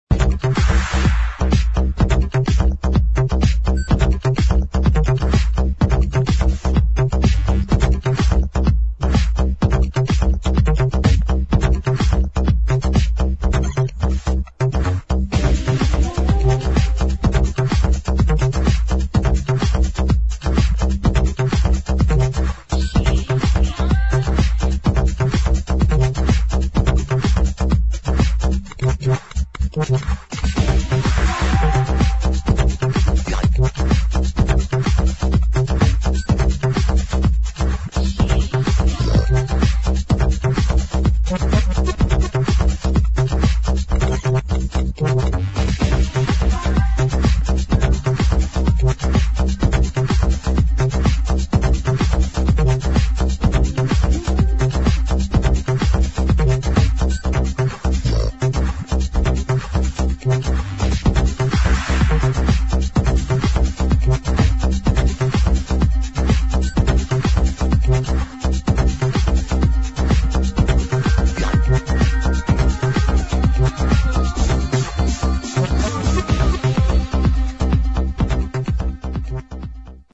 [ HOUSE / ELECTRO ]
フレンチ女性ヴォーカル・エレクトロ・ハウス・チューン！